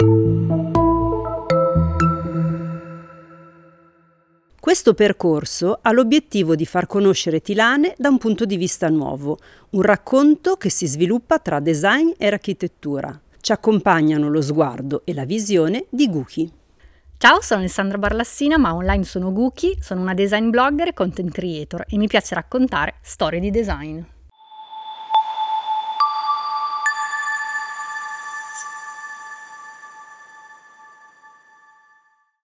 Visita guidata ad alta voce